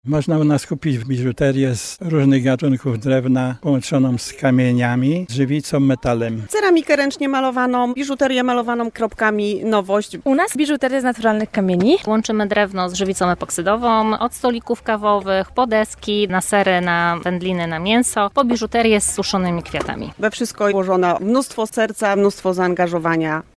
1-WYSTAWCY-Pchli-targ-Ksiezy-Mlyn-.mp3